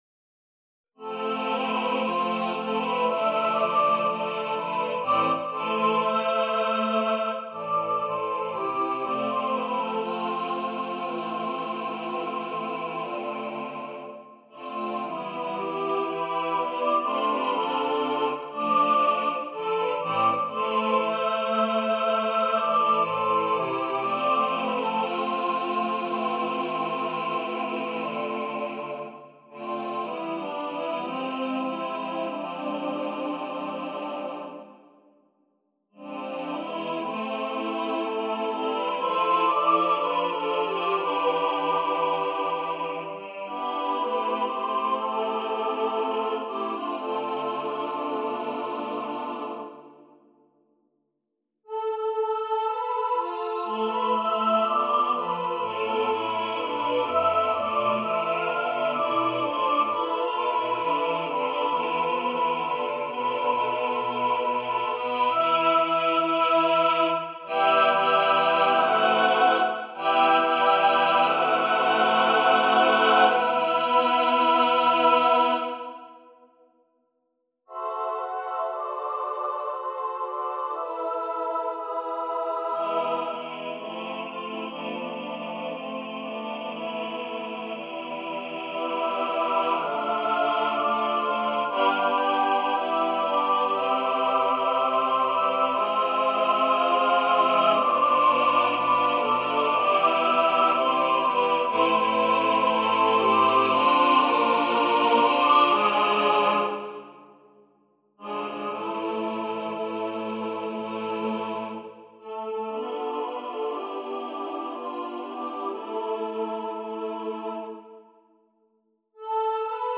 for SATB Chorus (divisi) unaccompanied